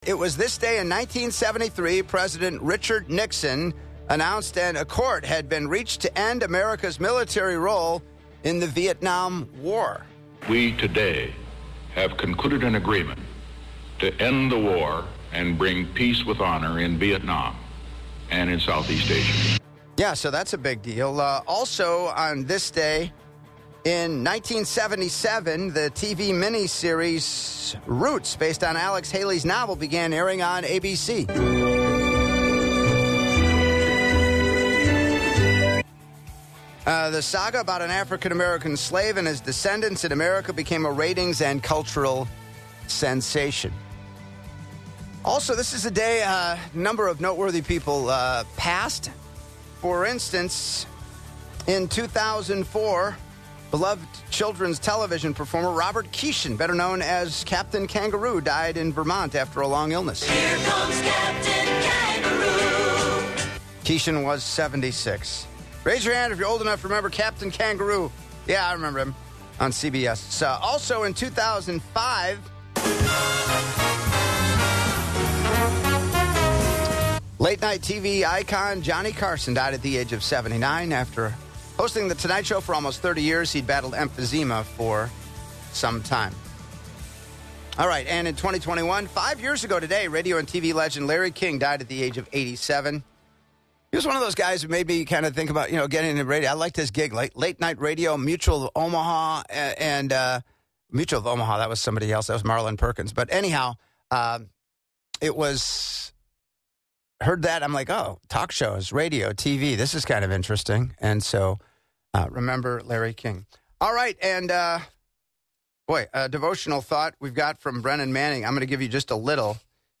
In-Studio Visit